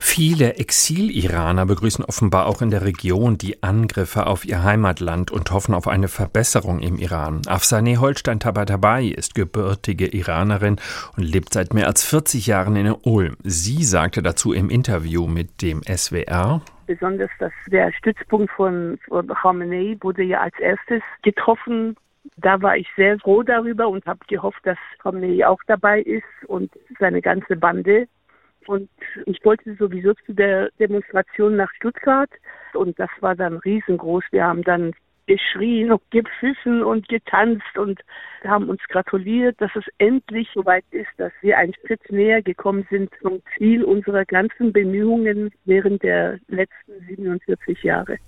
Ihre Reaktion auf die ersten Meldungen vom Wochenende ist eindeutig: "Ich habe morgens das Radio angemacht und mich sehr gefreut, als ich gehört habe, dass Teheran an bestimmten Plätzen angegriffen wurde", berichtet die 73-Jährige dem SWR im Interview.